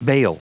Prononciation du mot bail en anglais (fichier audio)
Prononciation du mot : bail